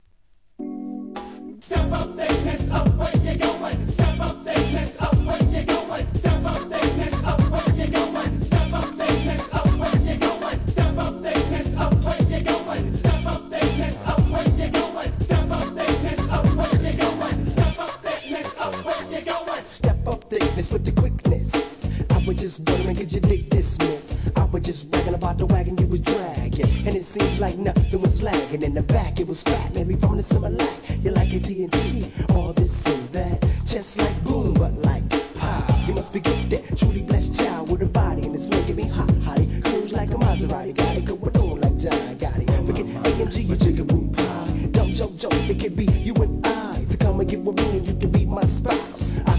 HIP HOP/R&B
心地よいネタ使いにフックといいNEW SCHOOLマナー!